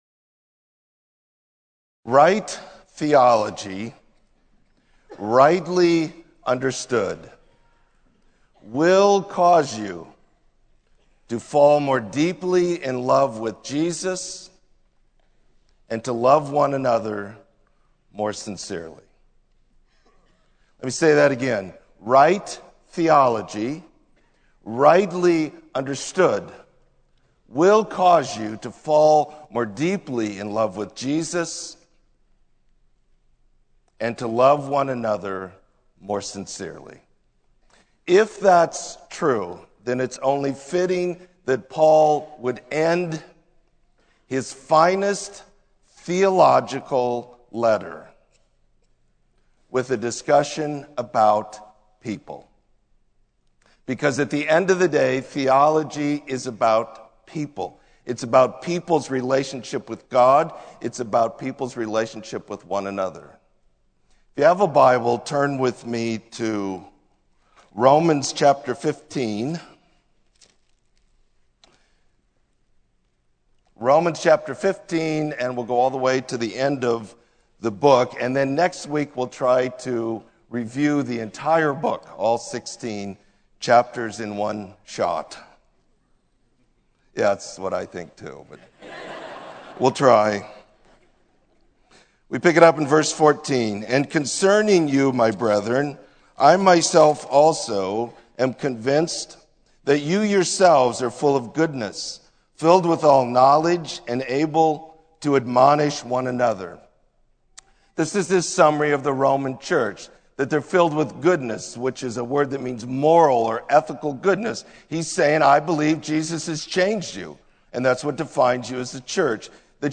Sermon: Proclaiming Christ